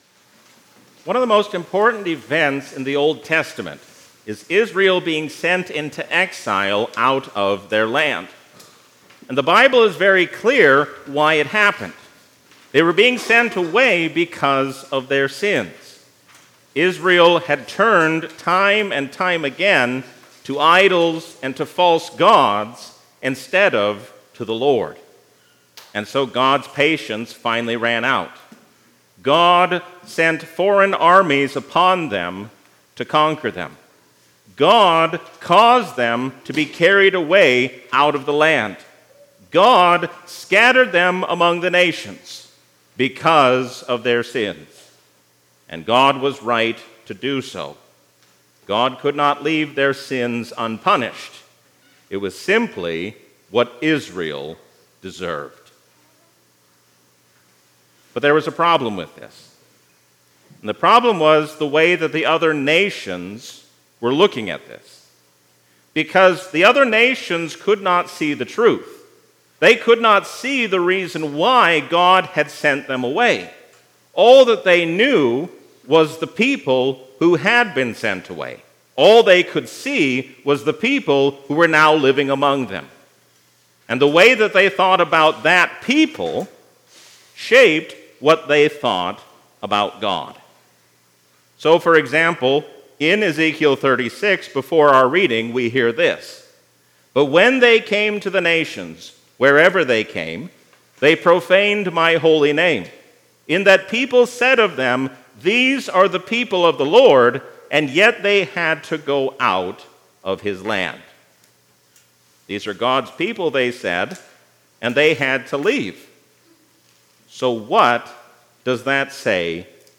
A sermon from the season "Pentecost 2024."